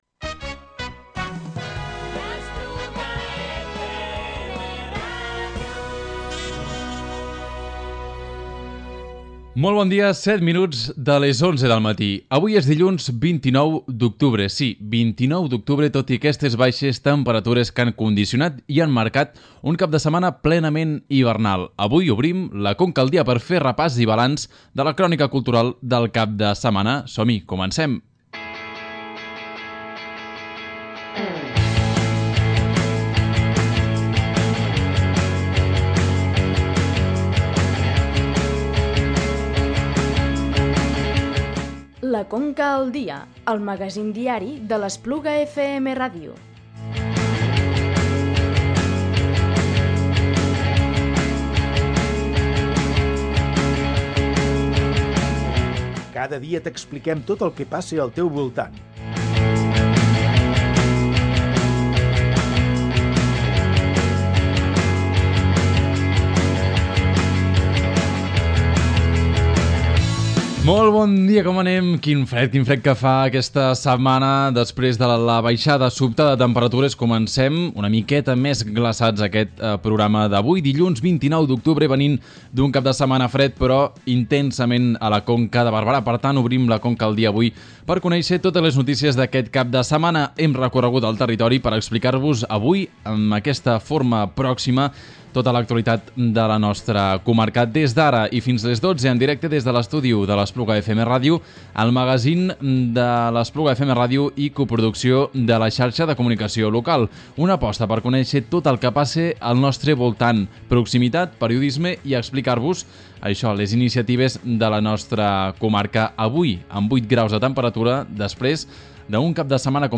A més a més, conversem amb dues de les tècniques de Limonium que han dinamitzat els primers tallers participatius de la Carta del Paisatge de la Conca i acabem el programa amb l’Excuse Me? EXTRA de la setmana.